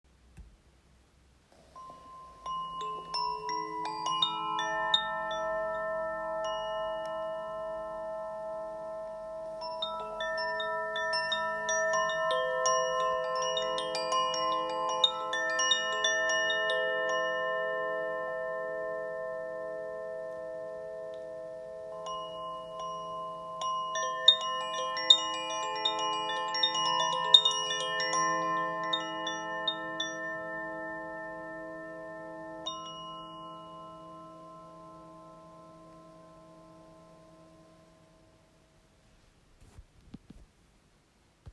Listen to the Air Koshi
Wind chime
• 8 integrated metal rods
• Aria Tuning: A, C, E, A, B, C, E, B